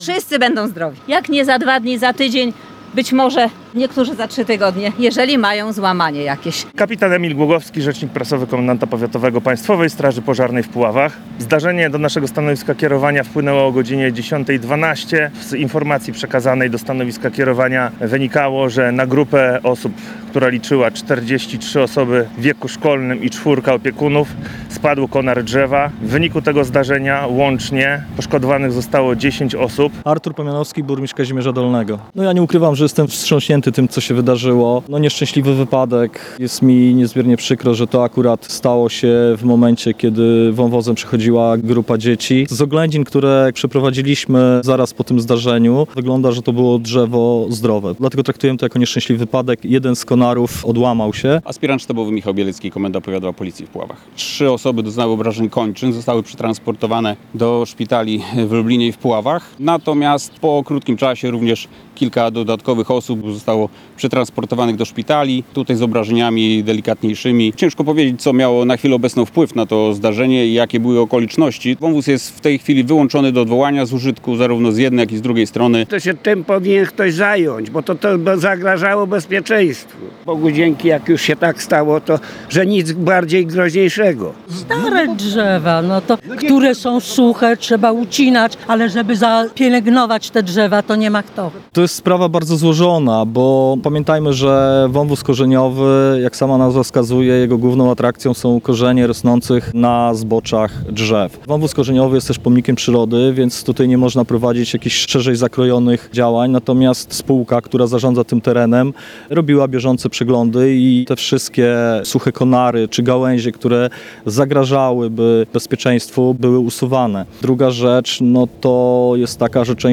Kilkadziesiąt minut po zdarzeniu jedna z opiekunek zapewniała w rozmowie z naszym reporterem, że nic bardzo poważnego uczniom się nie stało.